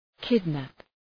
Προφορά
{‘kıdnæp}